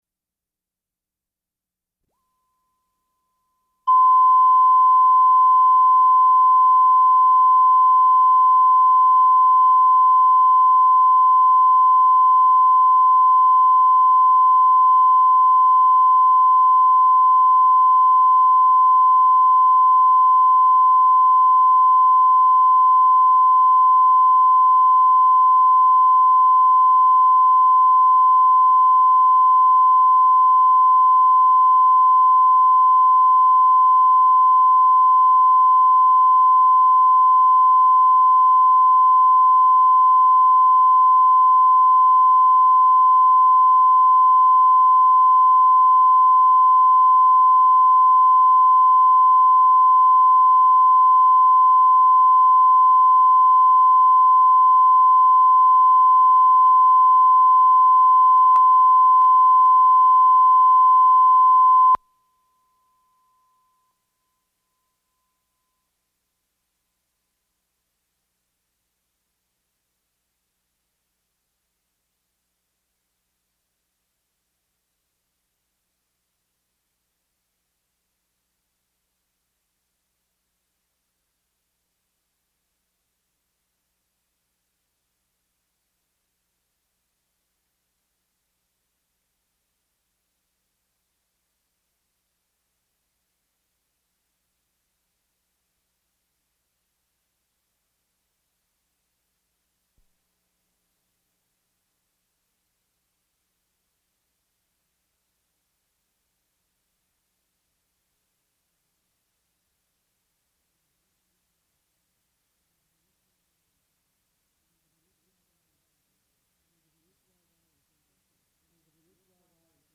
Rapper and Actor Ice-T. | Fresh Air Archive: Interviews with Terry Gross